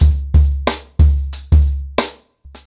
TrackBack-90BPM.5.wav